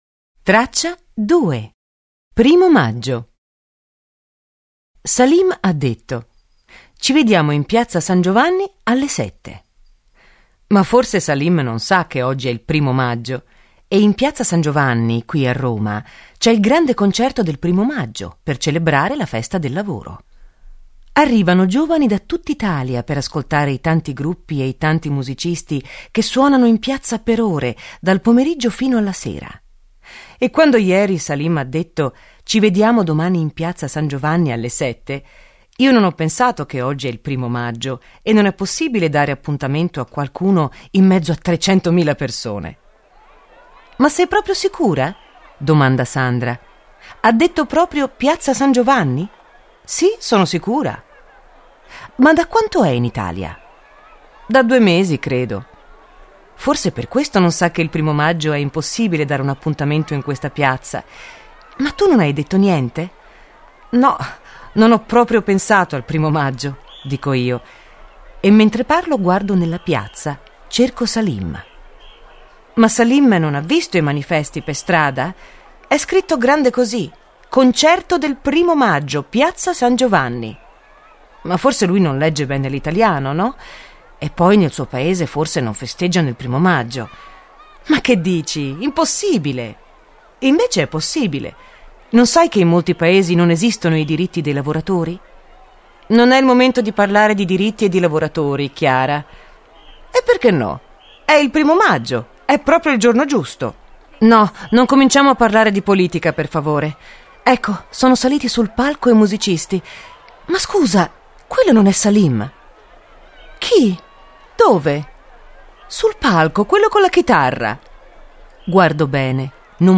Il volume fa parte della serie STORIE collana Italiano Facile, letture graduate per studenti stranieri con esercizi e versione audio del testo, con voci di attori professionisti ed effetti sonori realistici e coinvolgenti.